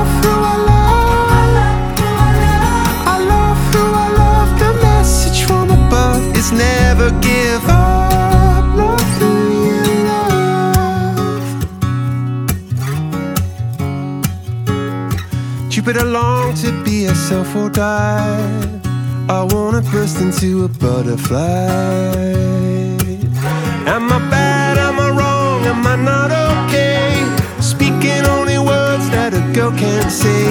Dance pop, hymnes de stade et ballade amoureuse